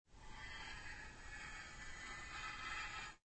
Descarga de Sonidos mp3 Gratis: arrastra 2.